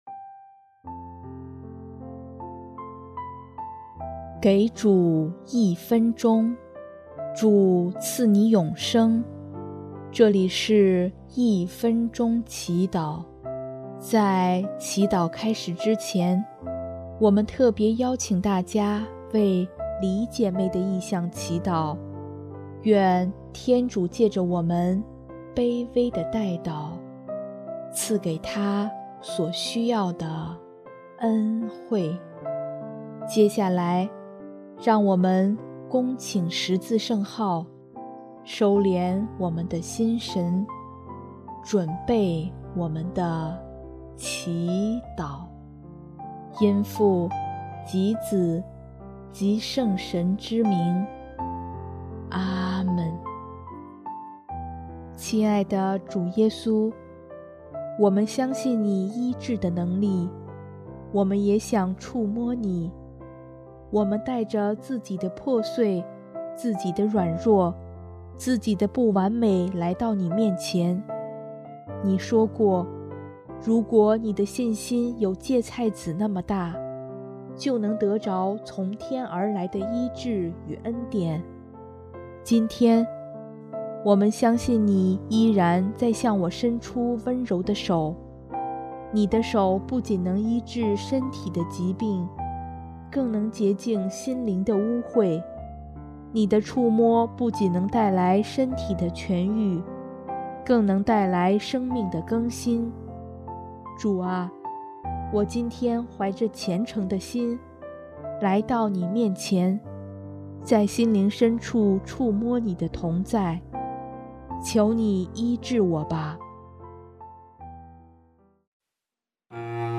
【一分钟祈祷】|1月22日 凡有病的人都涌过来要摸他